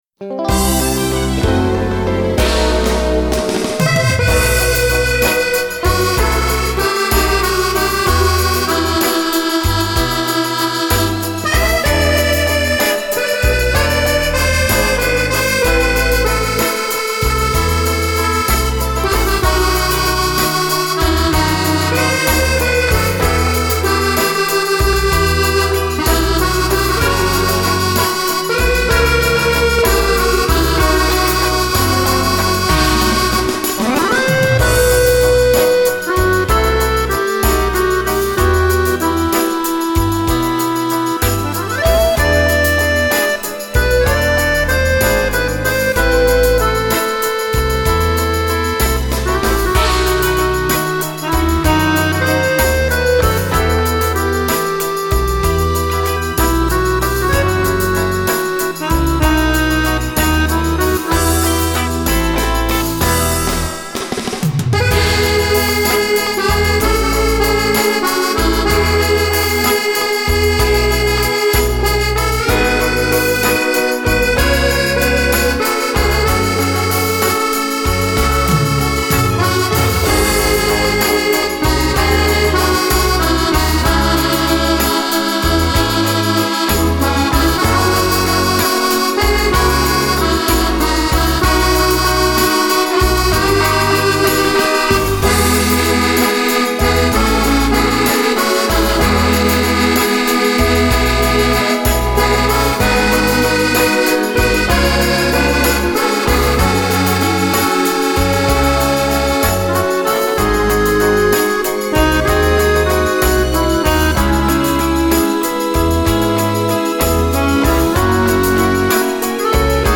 version accordéon